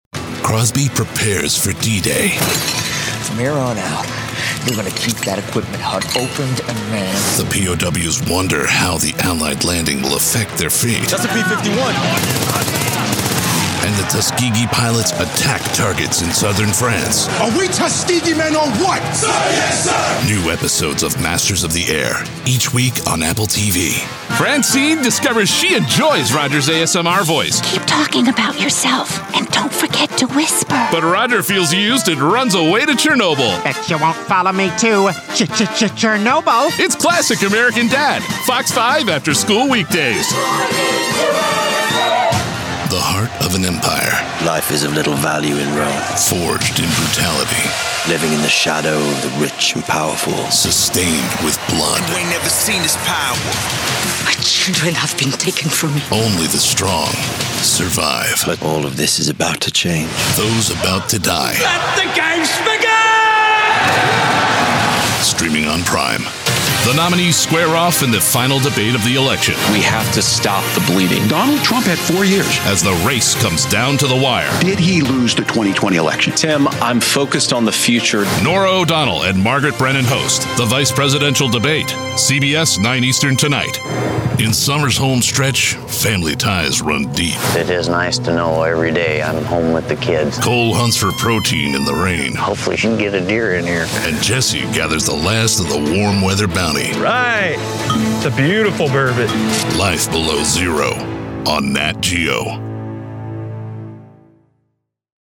Sou um homem com um grande coração, uma ampla gama e uma voz poderosa.
Autoritário
Confiável
Esquentar